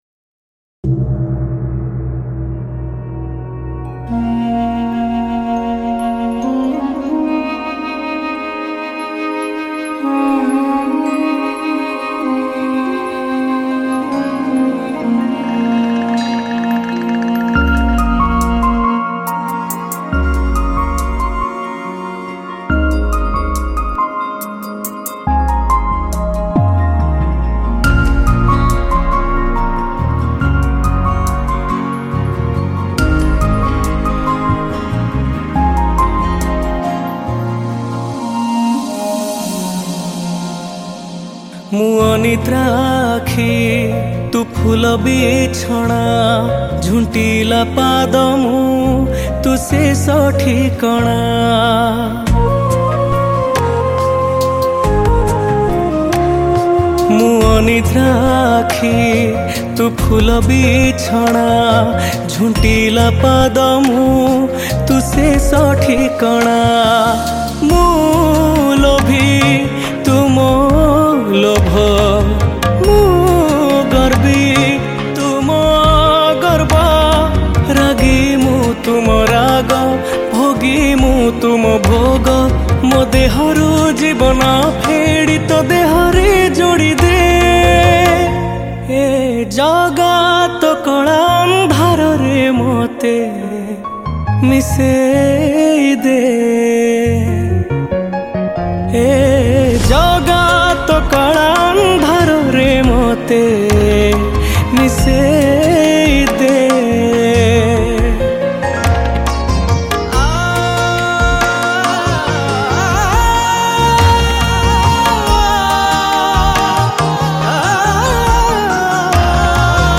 Key Board